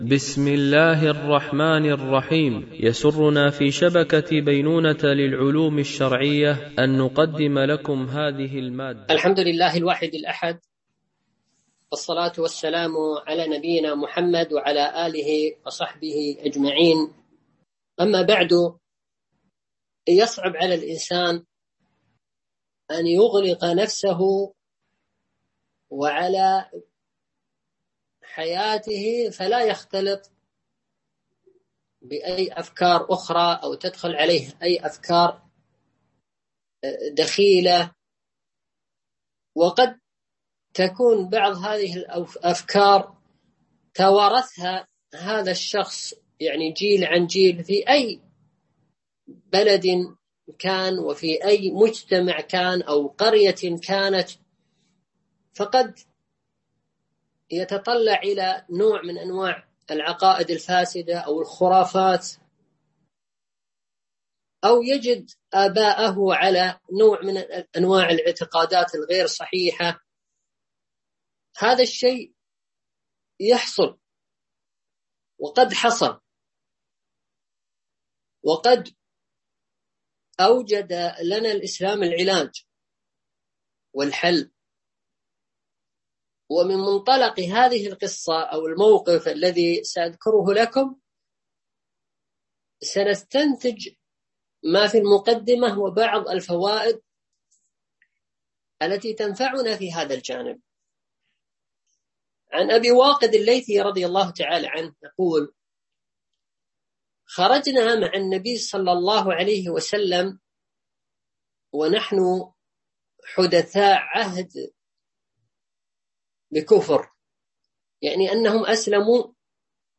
سلسلة محاضرات نسائم إيمانية وقيم أخلاقية
MP3 Mono 22kHz 64Kbps (VBR)